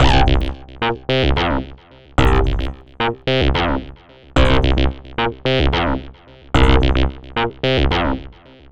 UR 303 acid bass 1 d.wav